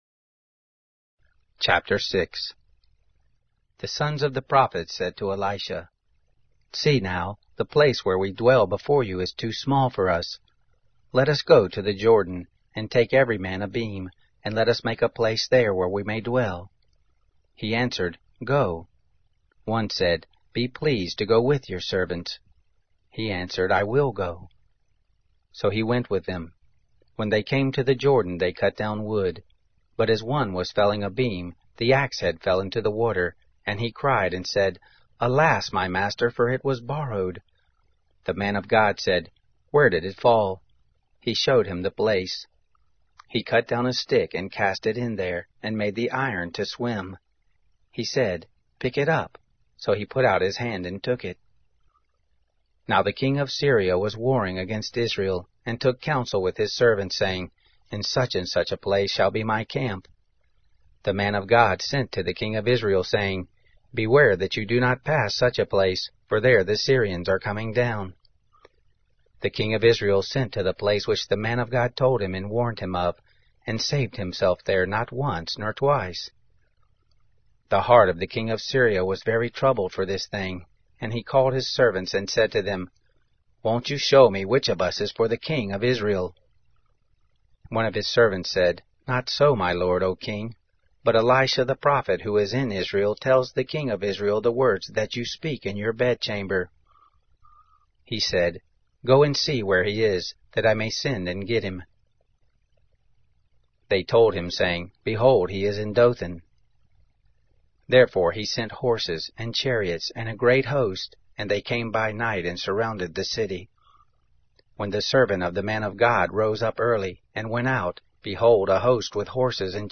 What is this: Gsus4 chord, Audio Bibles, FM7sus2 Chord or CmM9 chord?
Audio Bibles